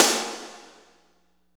49.06 SNR.wav